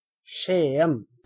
Skien (Norwegian: [ˈʂêːən]